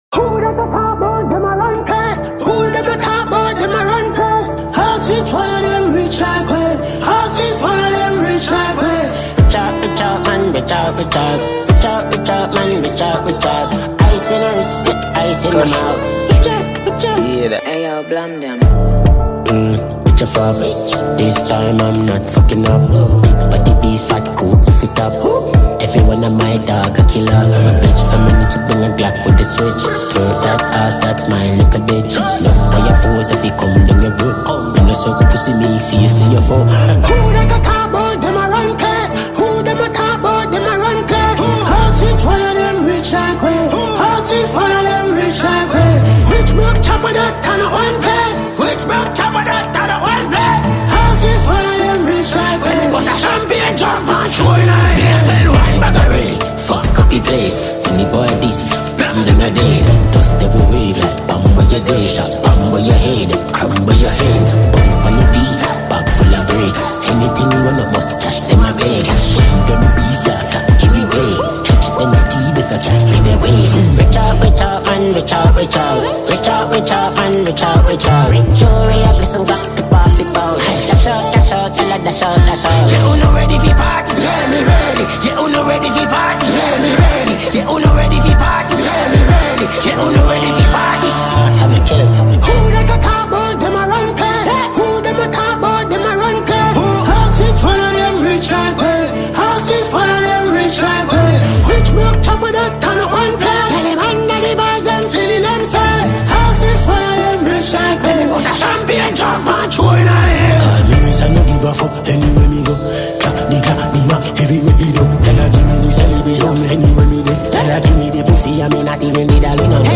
Jamaican dancehall musician